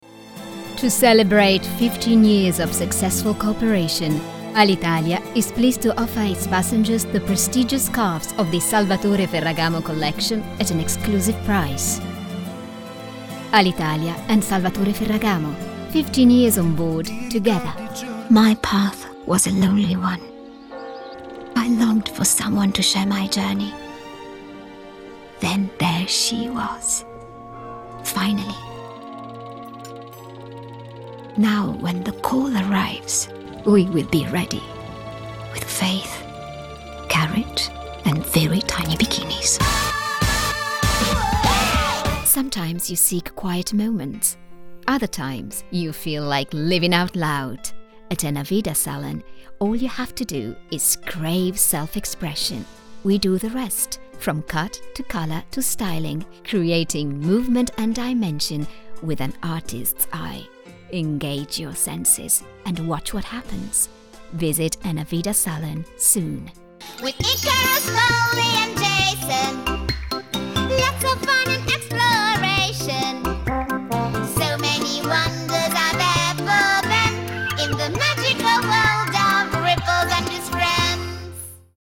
Sprecherin italienisch, bright, friendly, charming, versatile, young, professional
Kein Dialekt
Sprechprobe: Sonstiges (Muttersprache):
female voice over artist italian, bright, friendly, charming, versatile, young, professional